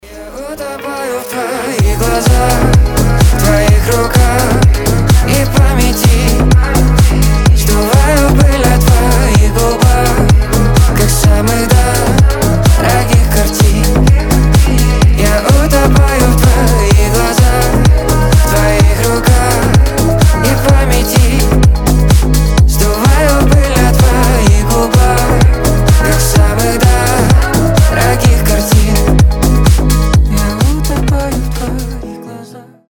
мужской голос
громкие